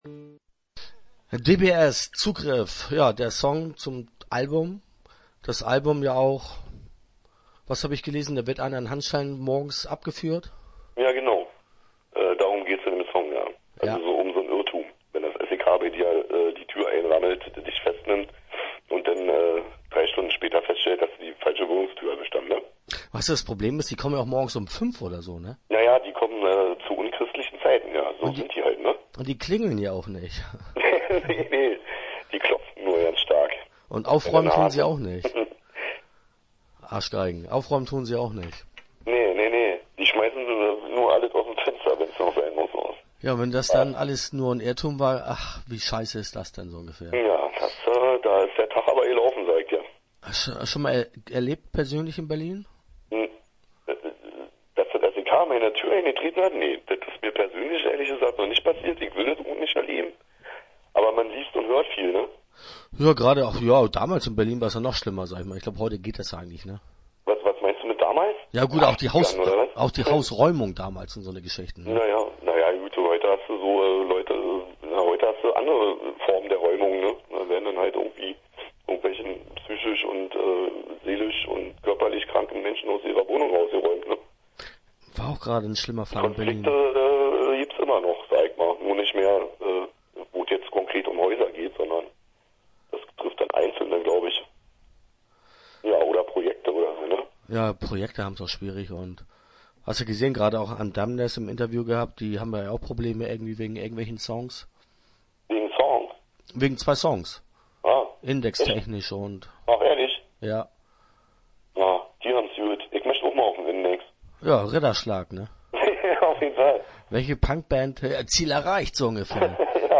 Start » Interviews » DxBxSx